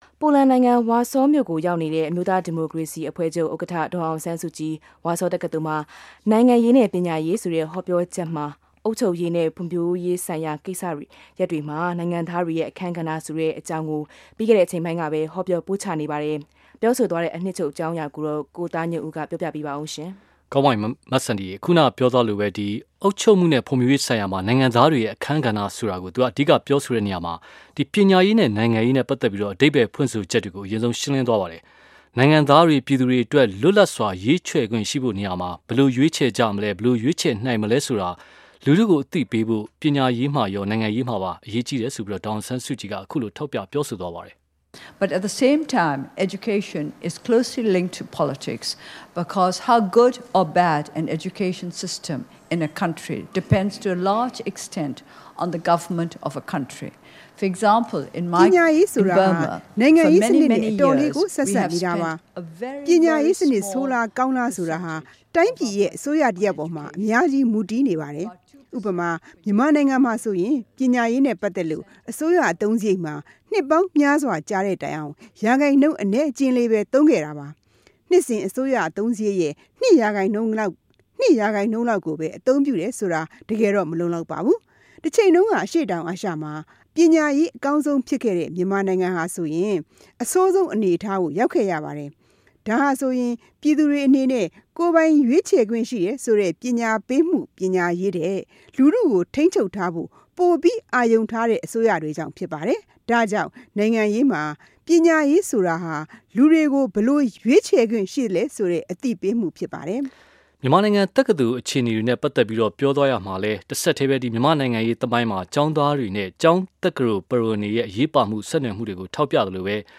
ဒေါ်အောင်ဆန်းစုကြည်-ပိုလန် ဝါဆောတက္ကသိုလ်မိန့်ခွန်း